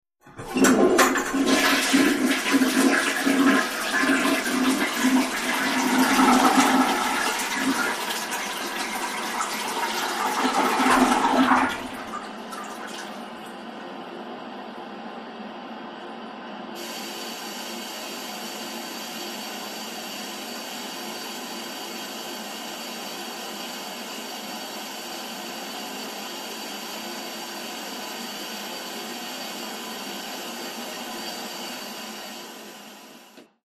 Toilet Flush 1; Typical Flush Routine; Handle Is Pressed, Water Then Flows Abruptly With Lots Of Water Gurgles Which Fades Into Typical Tank Refill Hums, Drips, And Hisses. Close Perspective